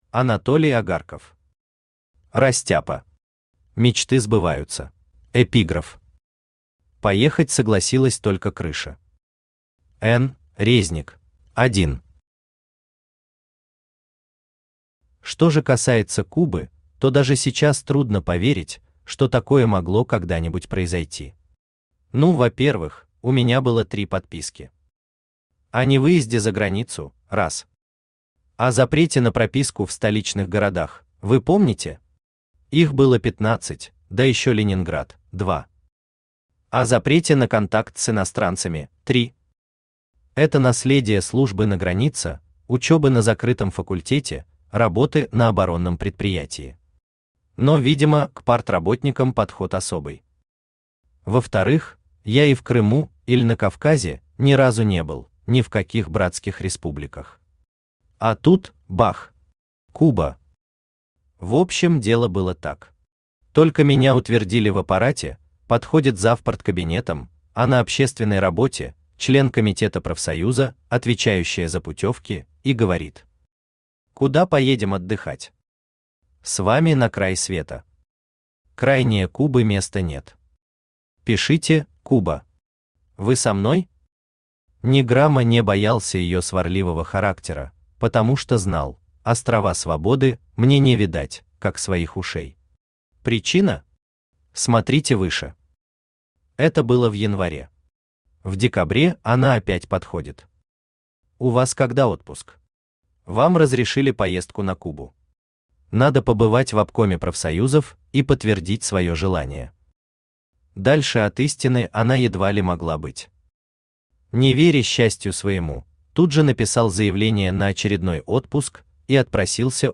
Аудиокнига Растяпа.
Мечты сбываются Автор Анатолий Агарков Читает аудиокнигу Авточтец ЛитРес.